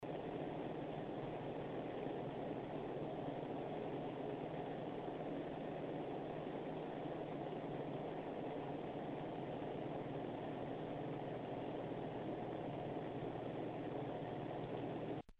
The Apack Zerotherm Nirvana 120 heatsink is moderately audible at full speed, and whisper quiet when the fan speed is dialed all the way down.
frostytech acoustic sampling chamber - low speed
standard waveform view of a 10 second recording. click on the headphones icon to listen to an mp3 recording of this heatsink in operation. the fan is rotating at 700 rpm.
Apack Zerotherm Nirvana 120 low 38.2 dB Intel/AMD